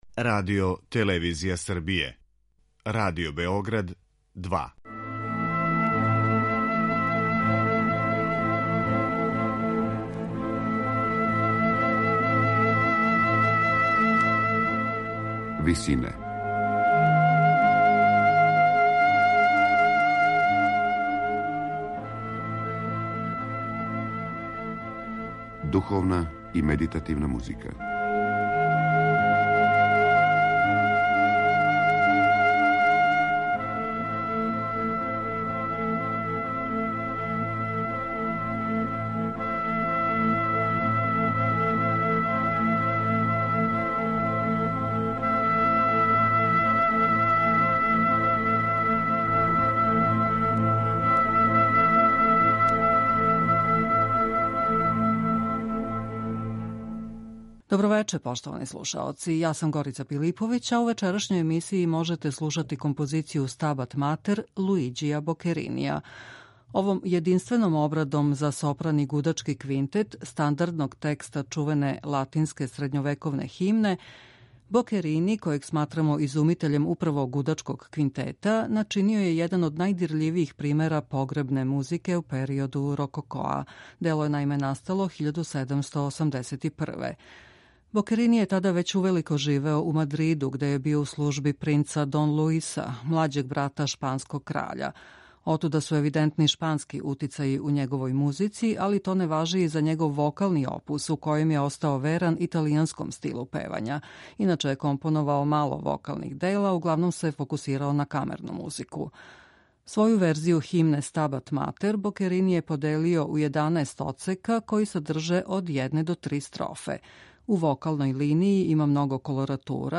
медитативне и духовне композиције аутора свих конфесија и епоха
за сопран и гудачки квинтет
погребне музике у периоду рококоа